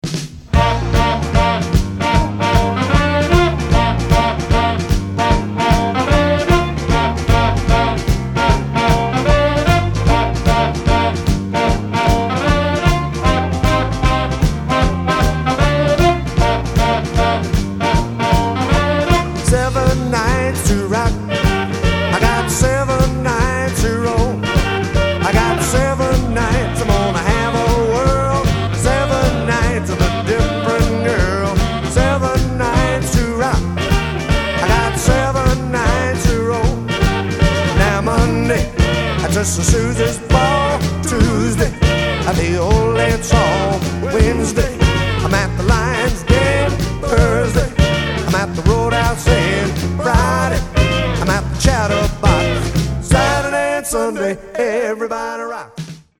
Chicago influenced harp playing